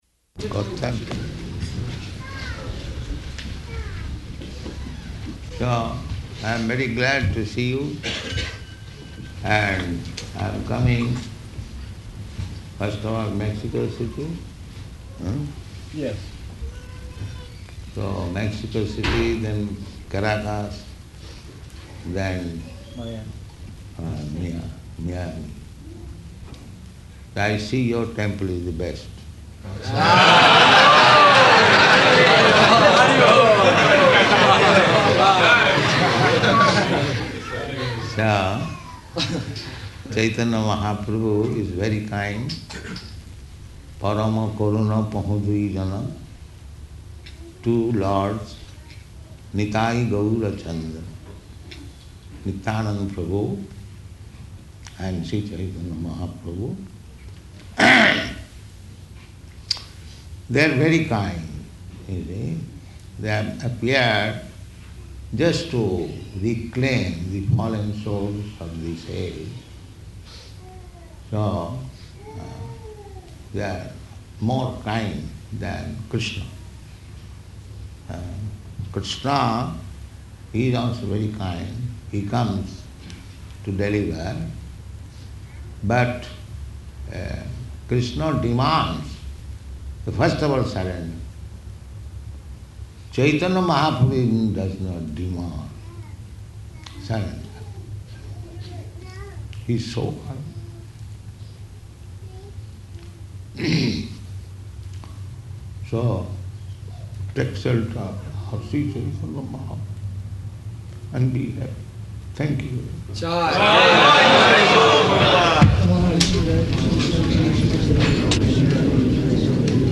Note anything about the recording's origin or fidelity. Location: Atlanta